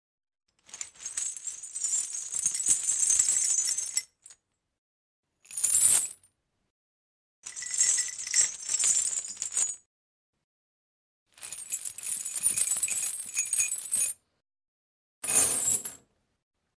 Звуки цепи
Звук грохочущей цепи